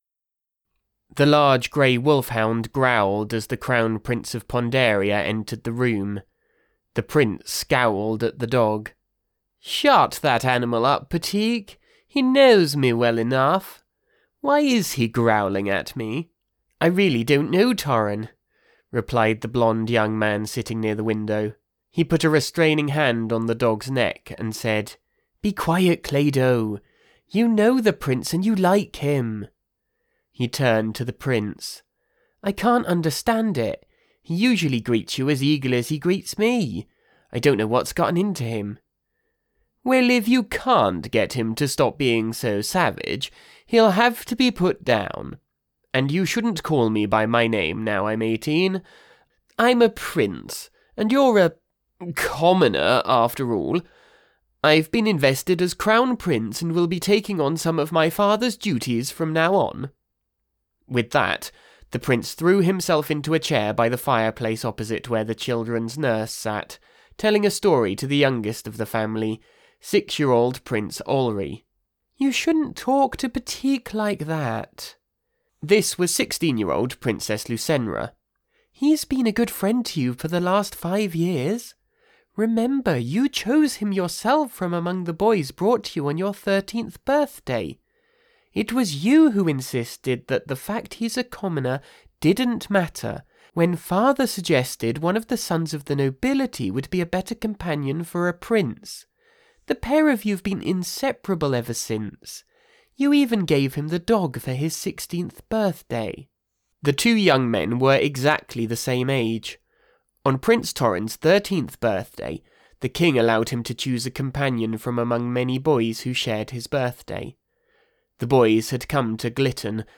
Or of course, if you want to quickly find some voice samples, look no further than the below.